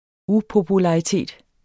Udtale [ ˈupobulɑiˌteˀd ]